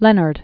(lĕnərd), Ray Charles Known as "Sugar Ray."